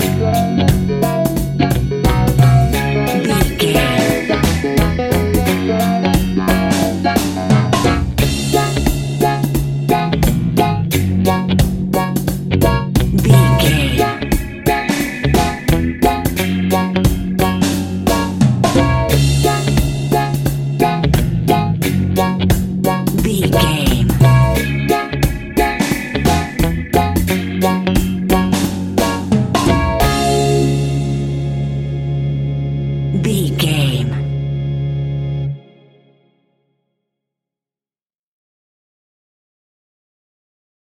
A groovy and funky piece of classic reggae music.
Aeolian/Minor
D♭
laid back
off beat
drums
skank guitar
hammond organ
percussion
horns